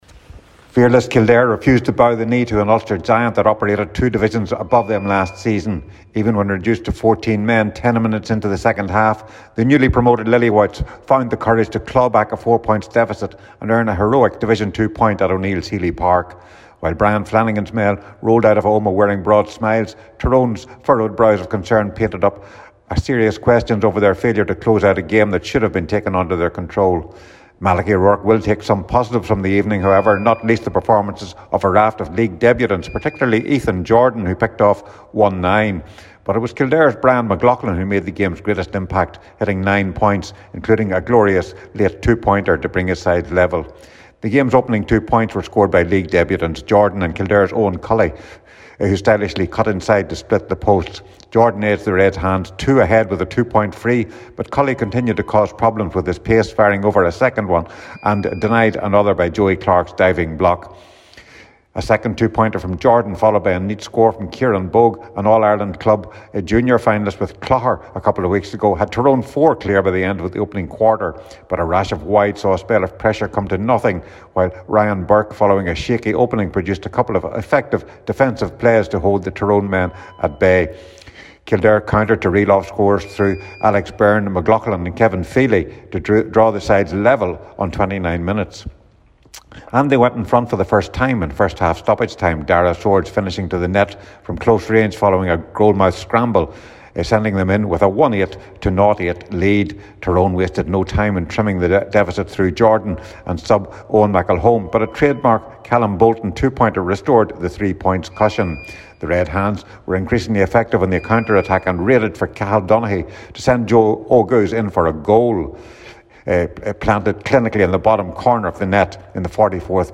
full time report from Omagh…